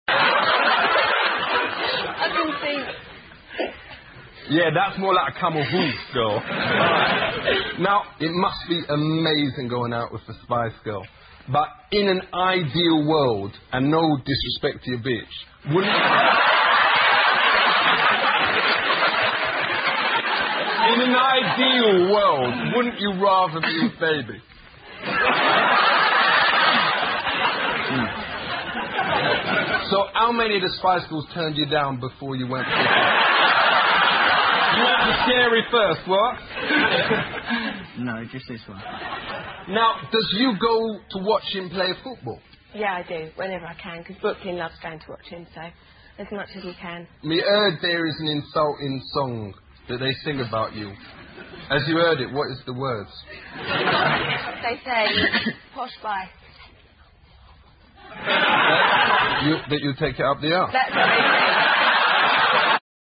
西部落：贝克汉姆夫妇超搞笑访谈-2 听力文件下载—在线英语听力室